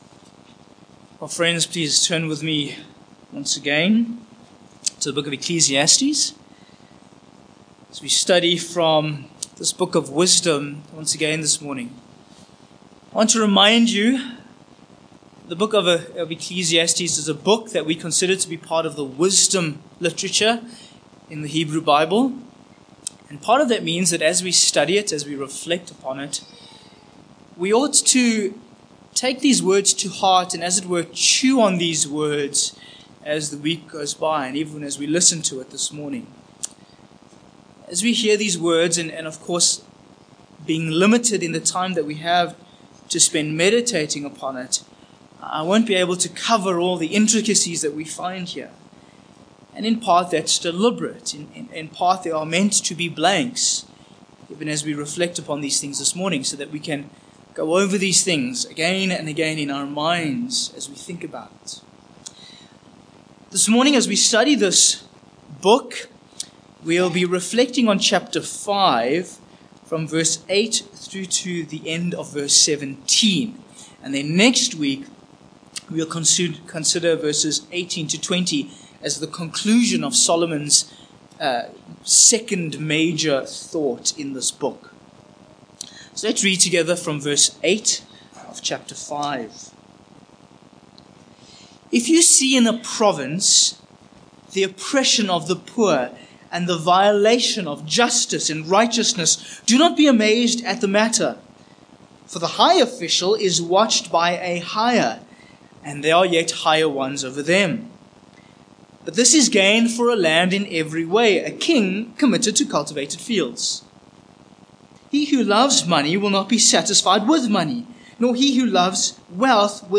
Sermon Points 1. State Capture v8-9